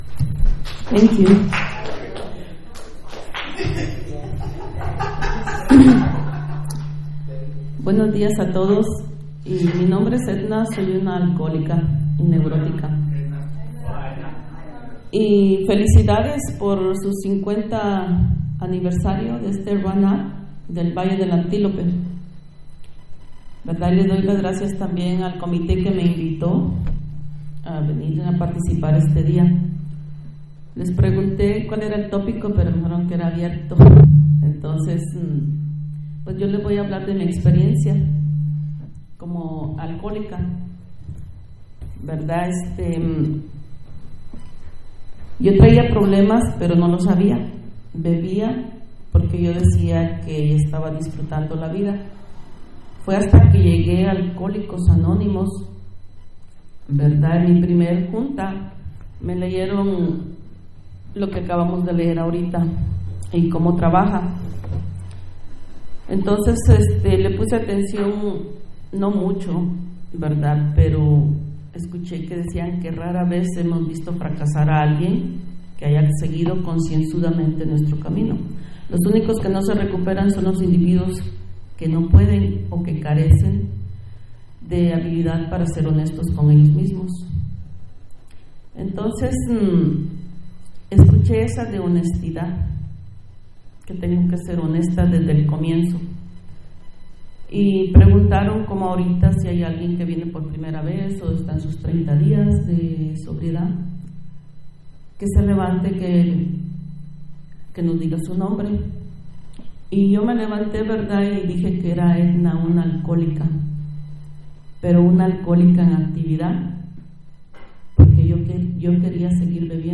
50th Annual Antelope Valley Roundup 2023